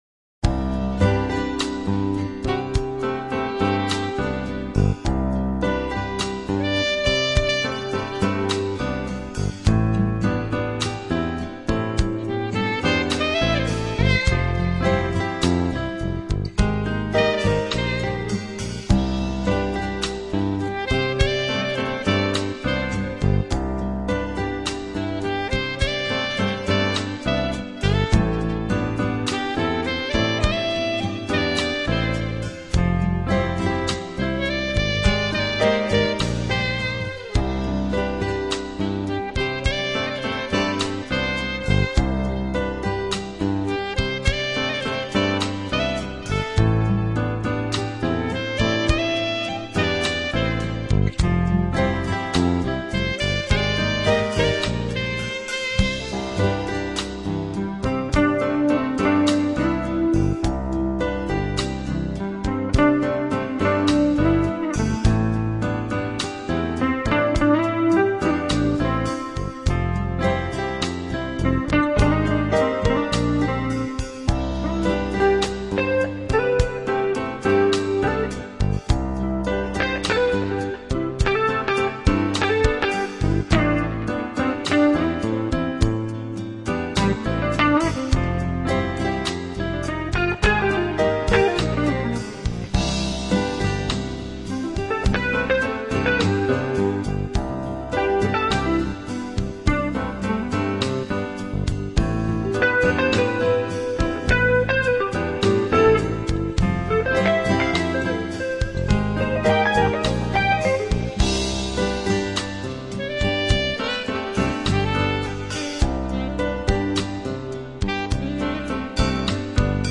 Джазовые мелодии саксофона